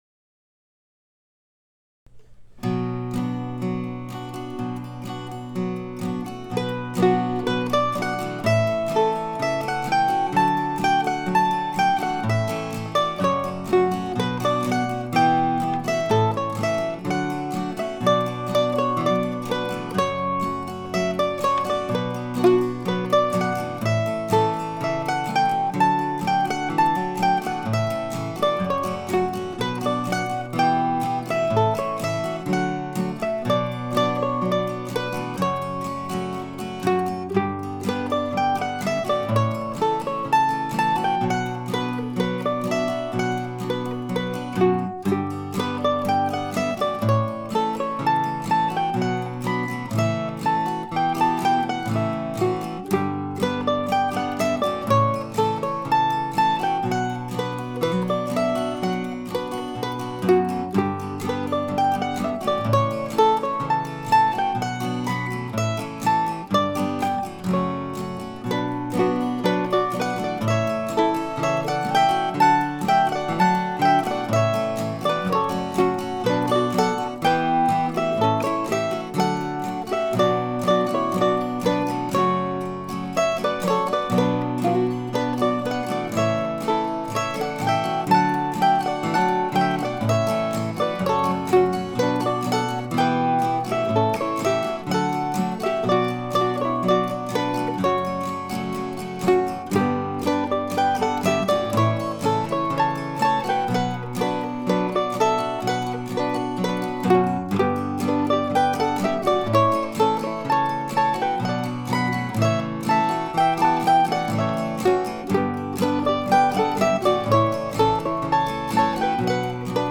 Despite my usual careful planning (just kidding) today's tune is the second blog entry in a row that is in the key of B minor and whose title ends in the words "at night."